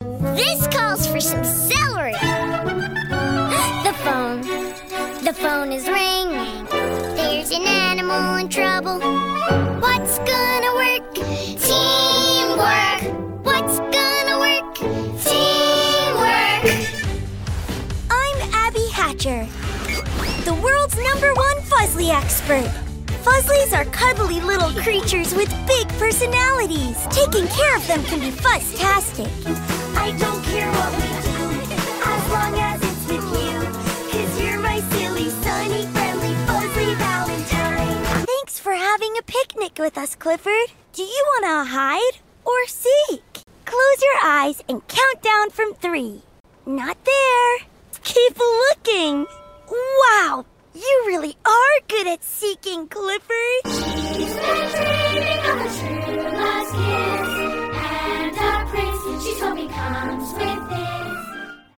Character Demo